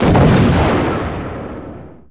Weapon Audio [Wav]
artillery_fire.wav
Artillery firing sounds
artillery_fire_603.wav